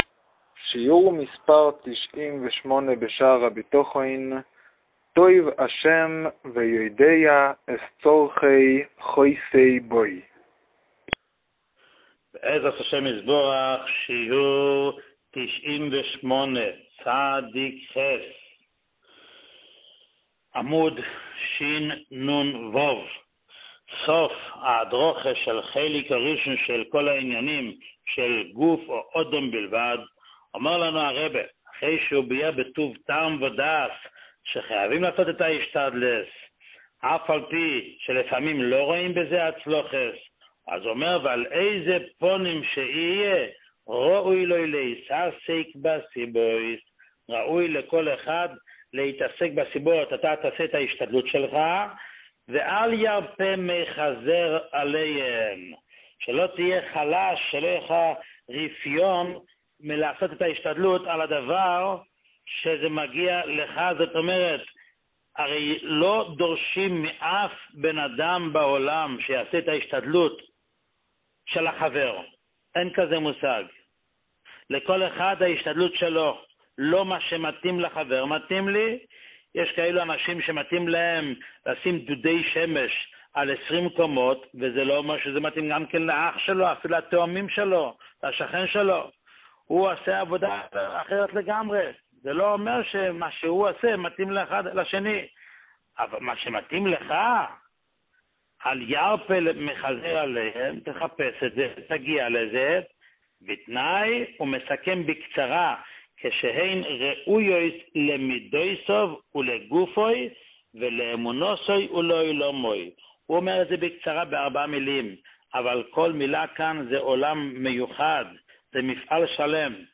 שיעור 98